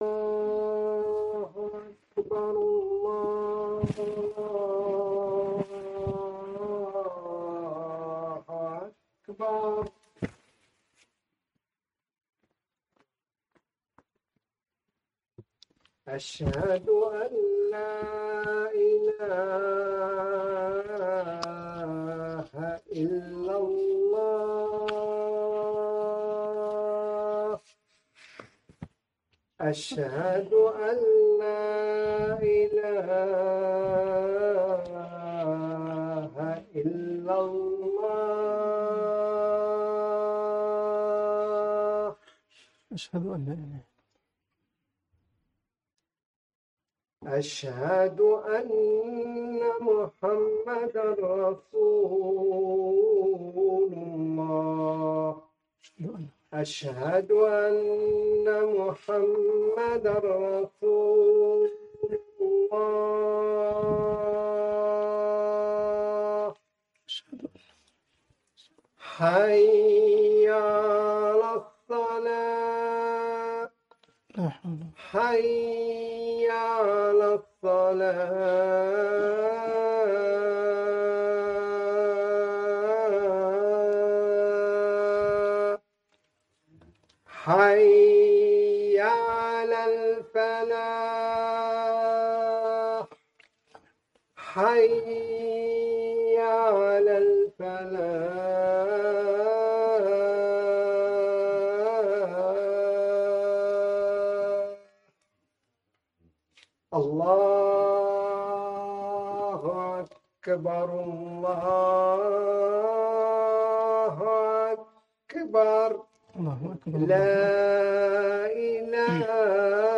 خطبة الجمعة